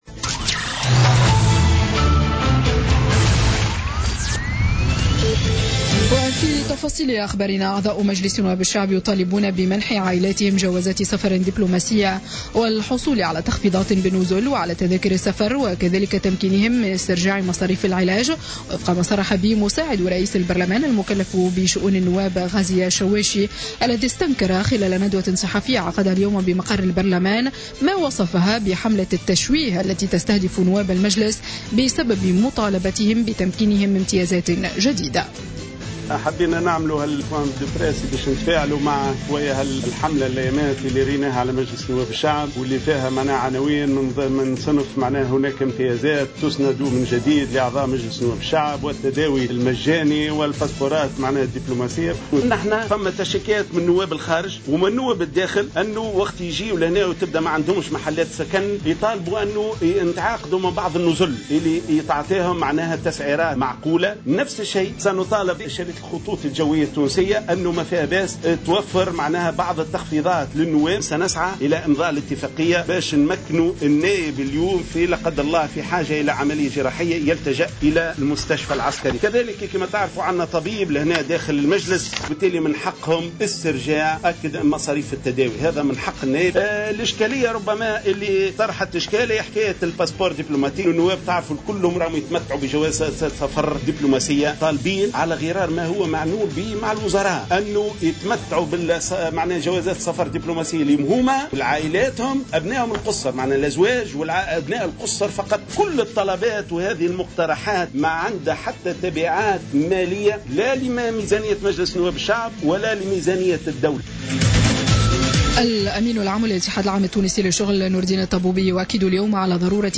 نشرة أخبار السابعة مساء ليوم الجمعة 24 فيفري 2017